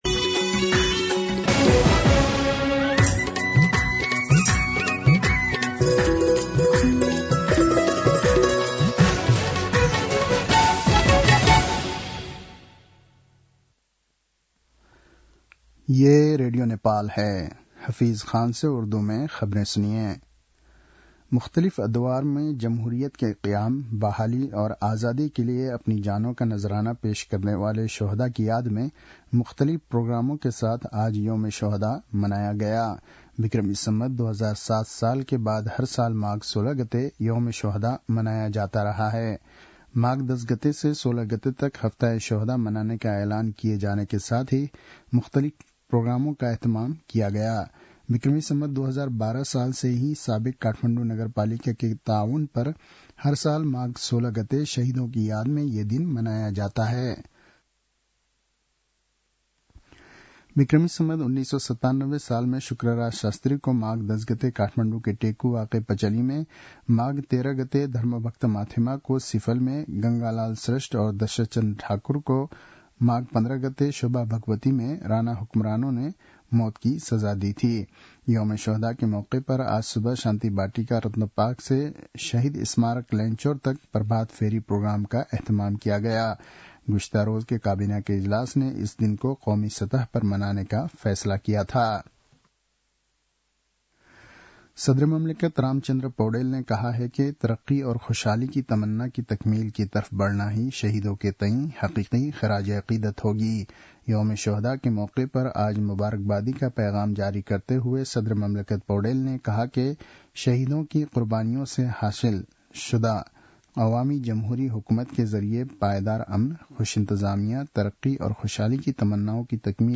उर्दु भाषामा समाचार : १७ माघ , २०८१
Urdu-News-10-16.mp3